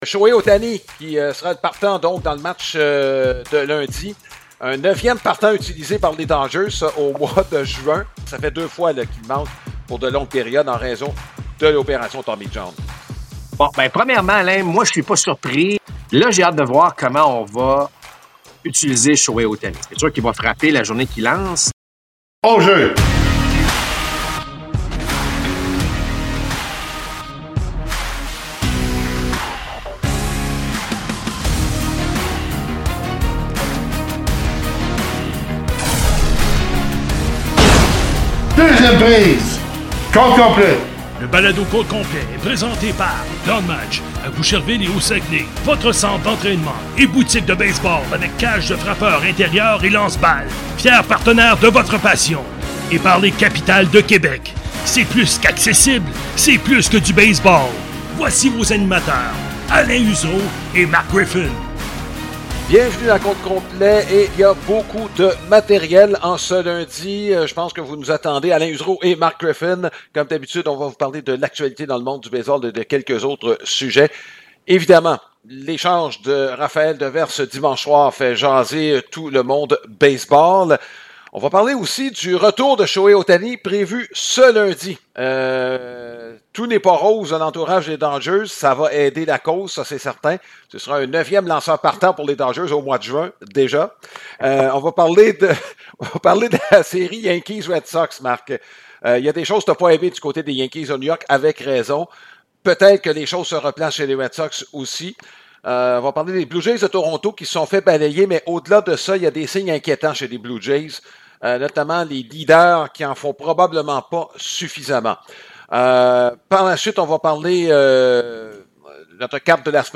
Compte complet | Entretiens